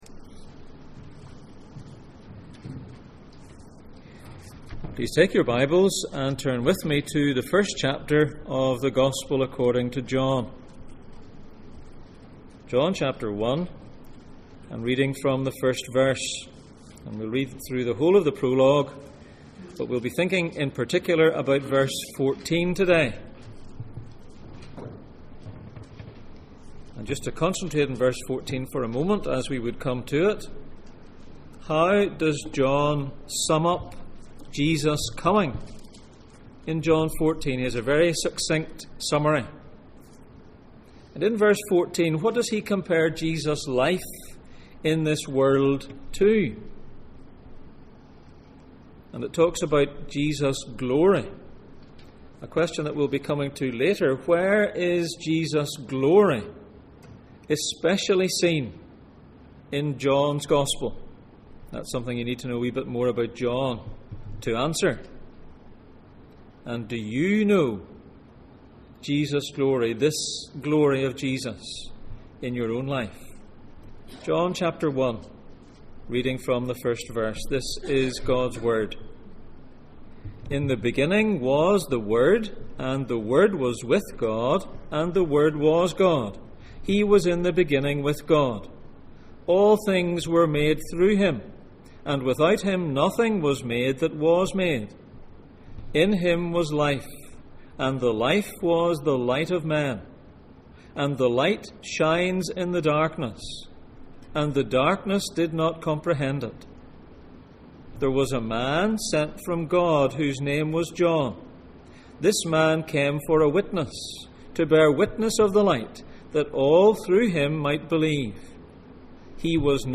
The Word Passage: John 1:1-18, Exodus 40:29, Exodus 40:34-35, John 13:31 Service Type: Sunday Morning